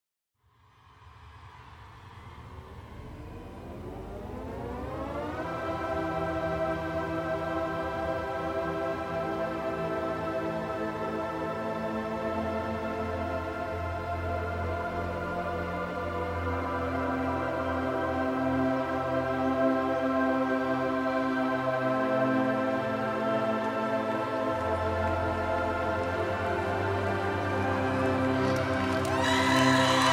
"frPreferredTerm" => "Chanson francophone"